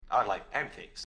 (Guy Saying) - i like pancakes | TLIU Studios
Category: ASMR Mood: Relax Editor's Choice